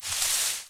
sounds / material / human / step / bushes1.ogg
bushes1.ogg